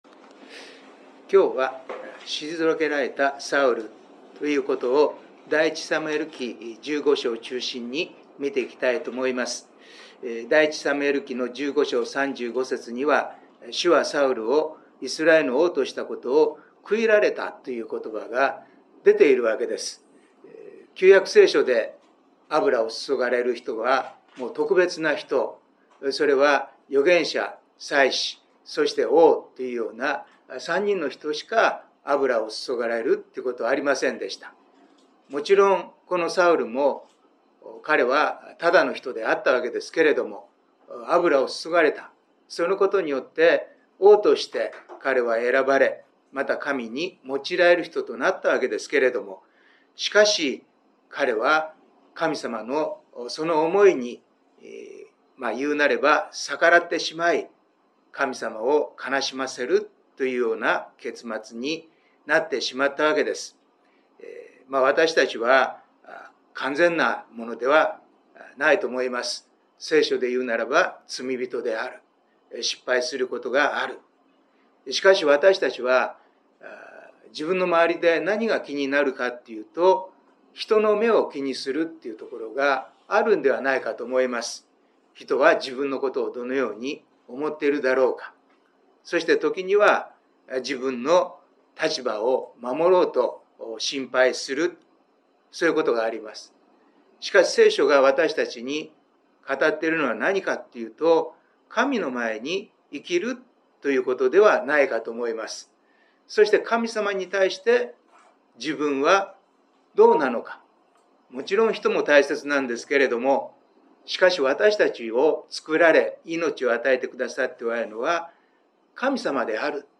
聖書研究祈祷会│日本イエス・キリスト教団 柏 原 教 会